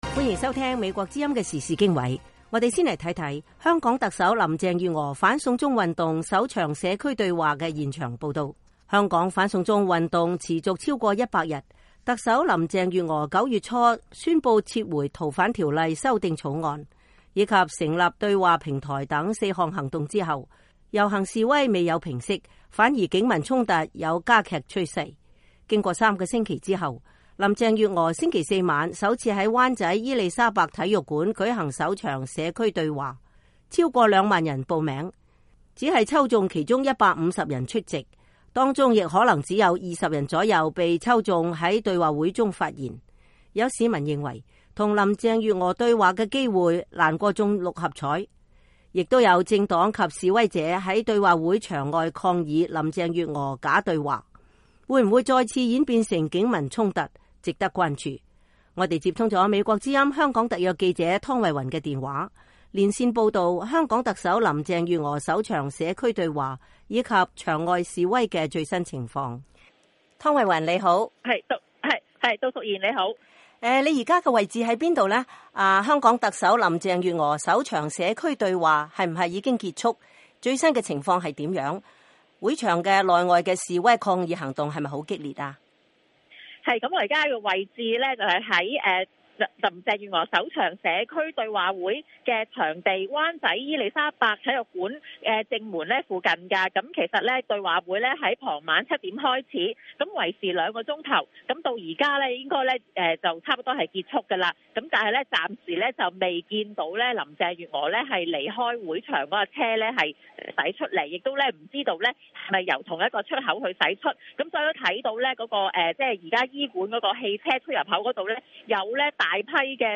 香港特首林鄭月娥反送中運動首場社區對話現場報道